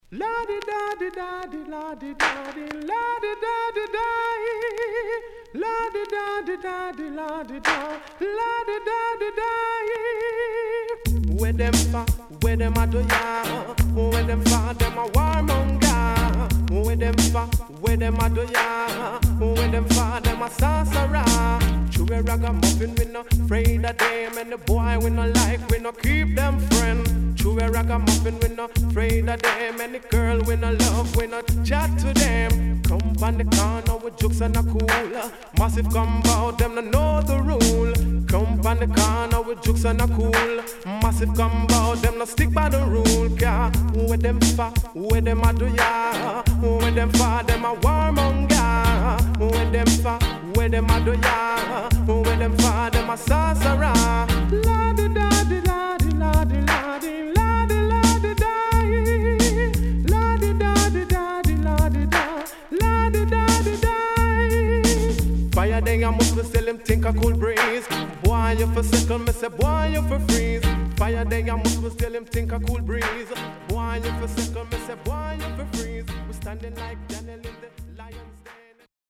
HOME > Back Order [DANCEHALL LP]
SIDE B:所々チリノイズがあり、少しプチパチノイズ入ります。